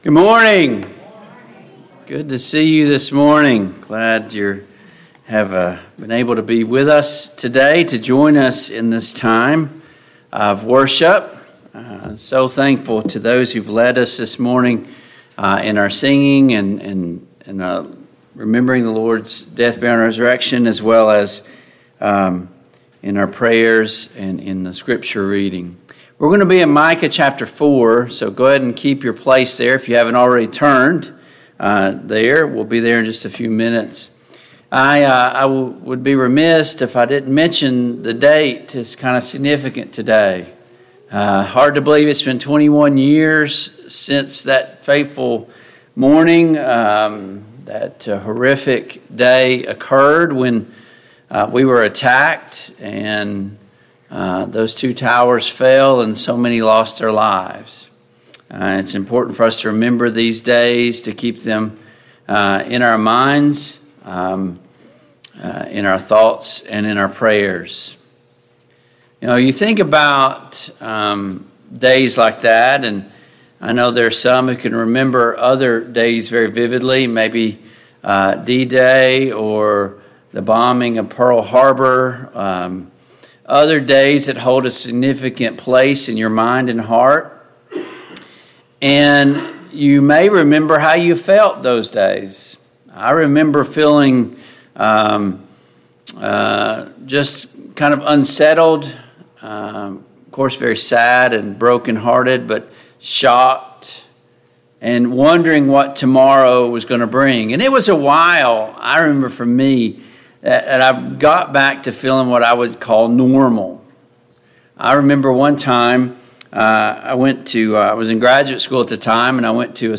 Service Type: AM Worship